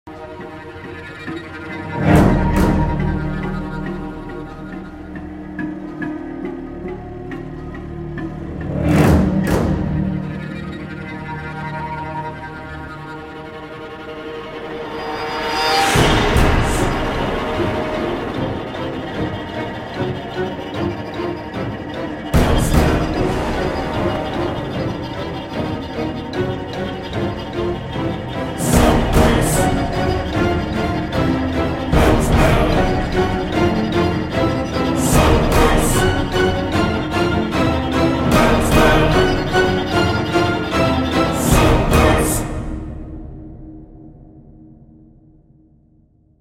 Tune in for a thrilling conversation about his career, social media influence, and heartfelt appreciation for his fans.